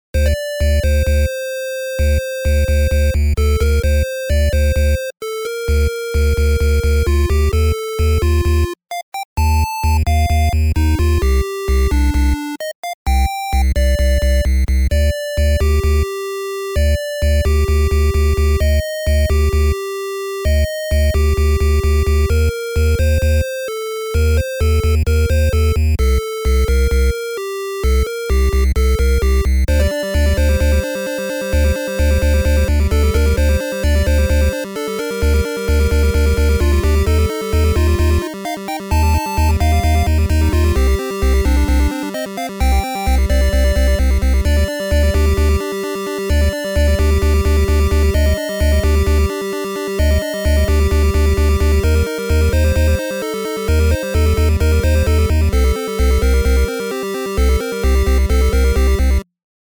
A Song for exploring Dungeons on your good old GB :P.